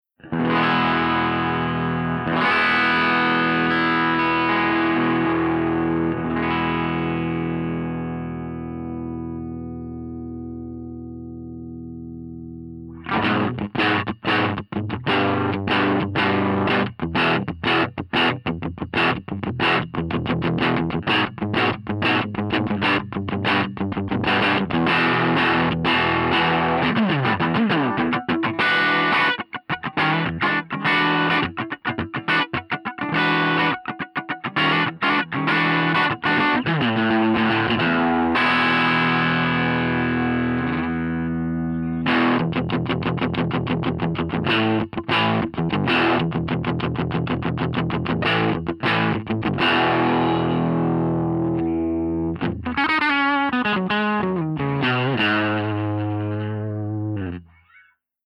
048_FENDER75_DRIVECHANNEL_SC.mp3